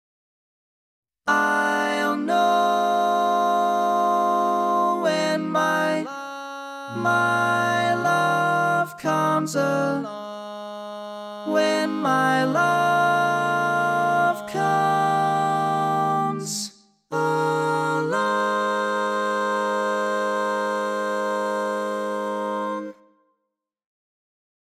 Key written in: A♭ Major
Type: Barbershop